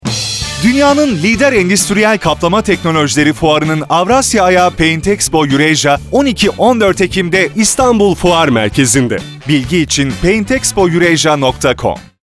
Radyo Spotlarımızdan Örnekler